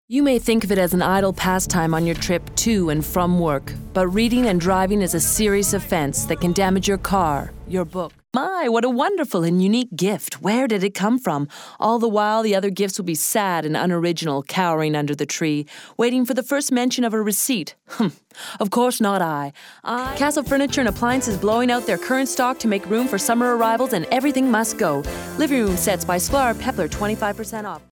Voice Demo